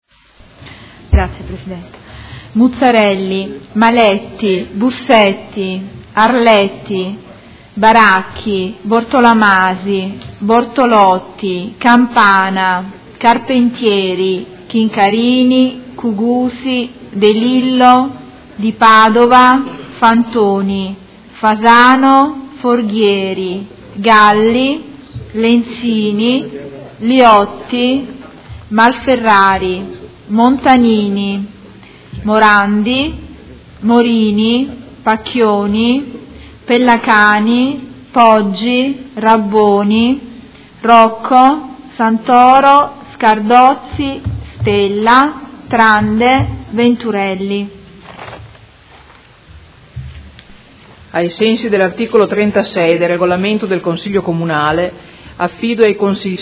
Appello
Segretario Generale
Seduta del 28 aprile 2016